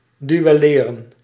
Ääntäminen
IPA : /ˈduː.əl/